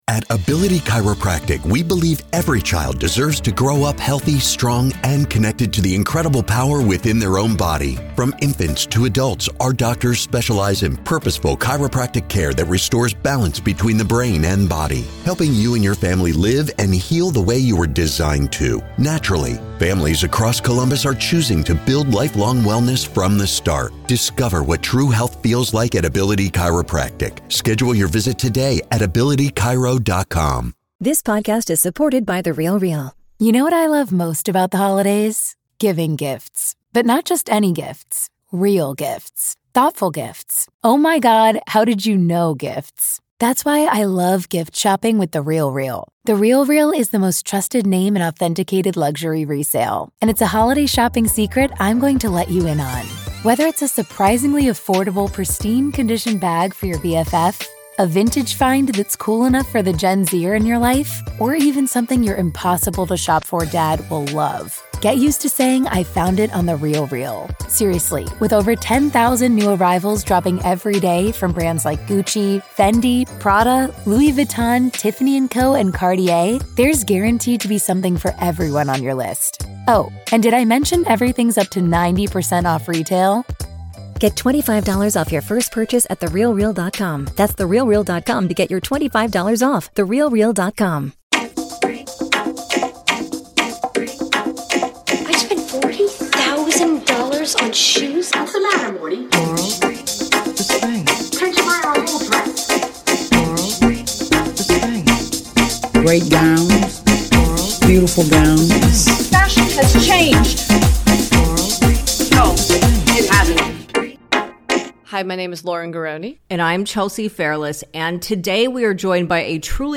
Today we are joined by our spiritual mother Candace Bushnell for an intimate conversation about being Carrie Bradshaw, the origins of Sex and the City, her IRL date with John Corbett, the heyday of New York nightlife, her new one-woman show, and more!